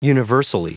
Prononciation du mot universally en anglais (fichier audio)